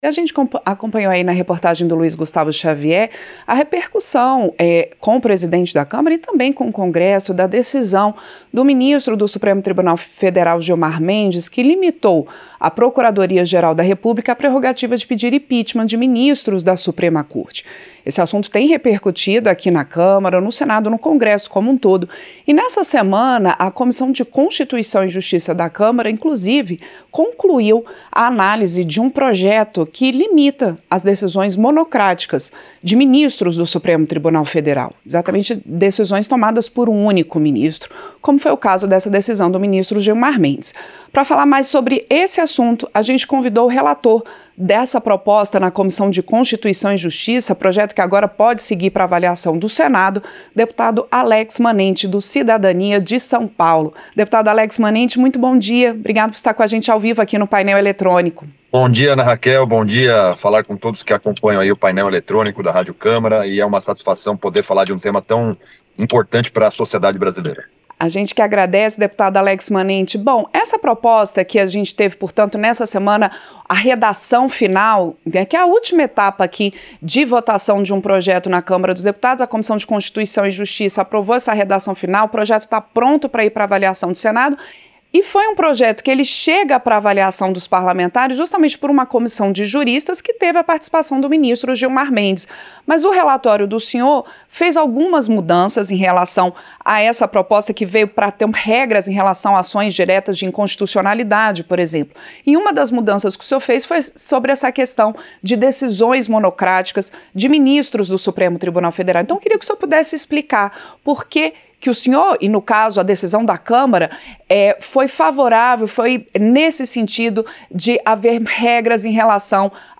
Entrevista - Dep. Alex Manente (Cid-SP)